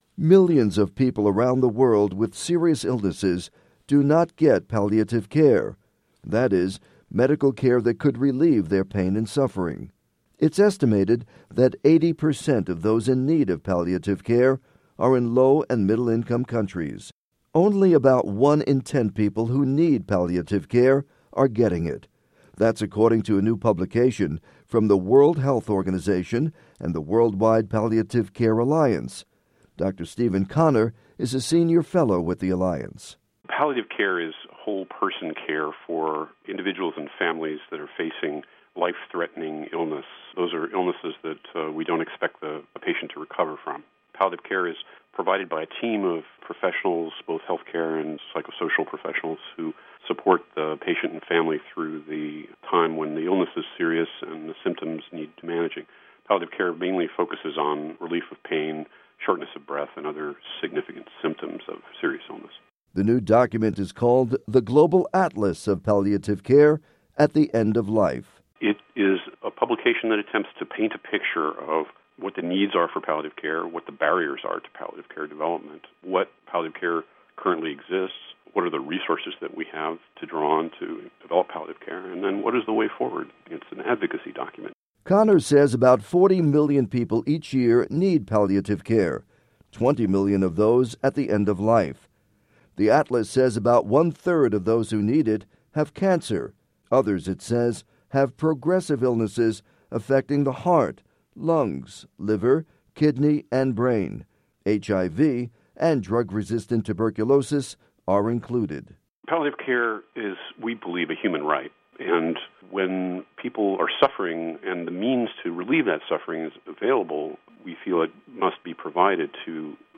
Voice of America (VOA News)